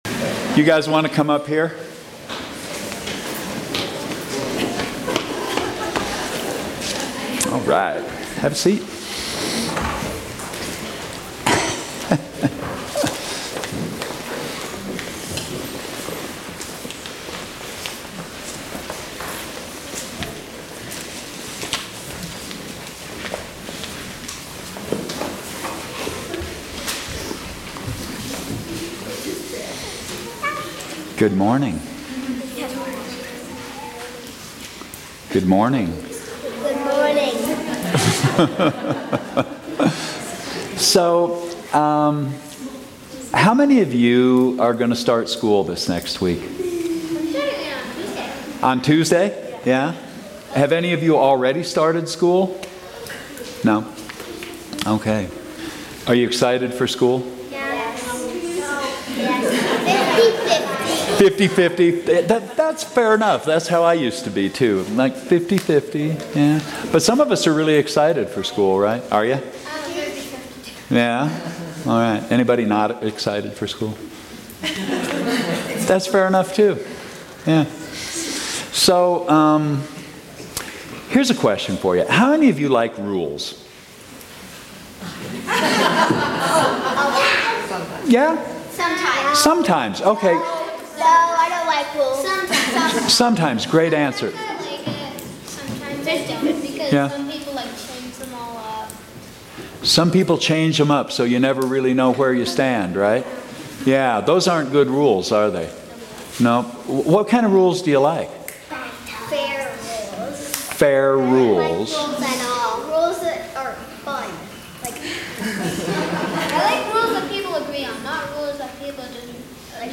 Children's Sermon
Sermons from St. John's Episcopal Church